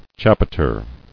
[chap·i·ter]